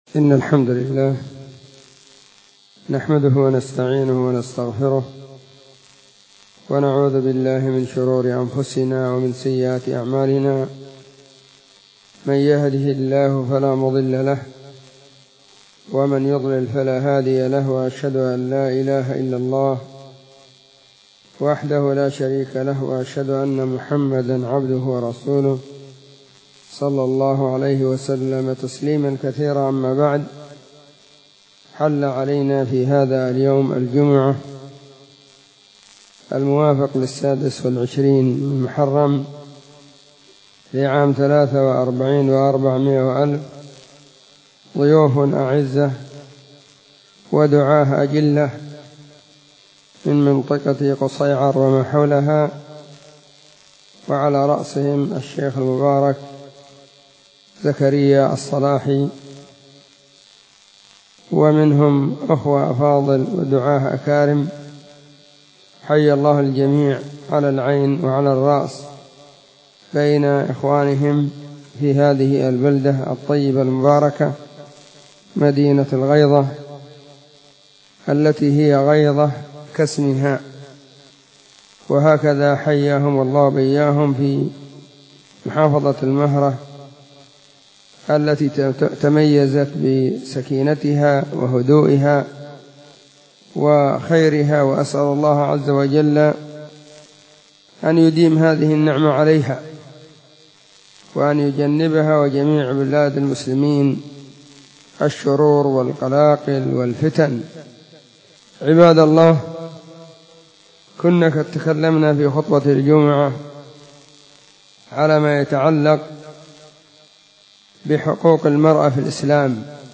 🎙فهذه محاضرة تتمة لخطبة الجمعة بعنوان; البُلغة في عناية الإسلام بحق المرأة.*
📢 مسجد الصحابة – بالغيضة – المهرة، اليمن حرسها •اللّـہ.
تتمة_لخطبة_الجمعة_البُلغة_في_عناية_الإسلام_بحق_المرأة.mp3